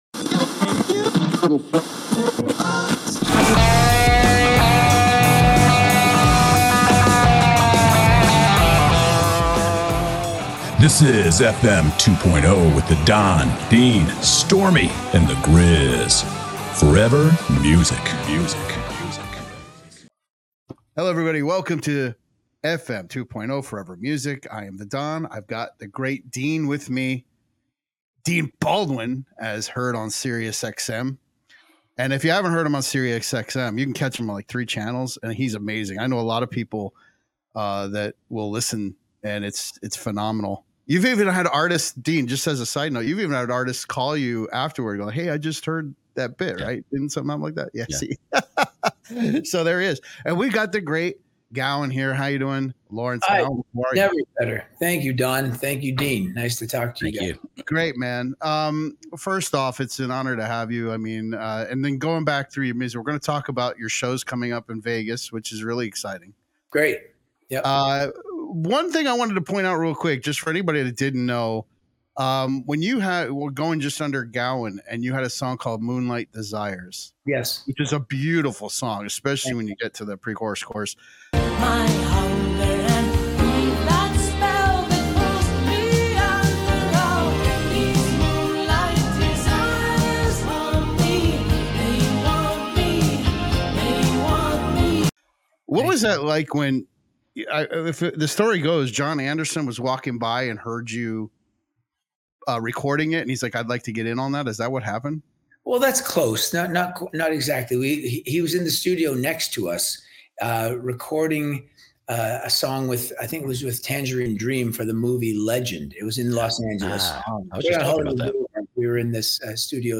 Lawrence Gowan (Vocals/Keyboards) of Styx joined the show for a lively music conversation.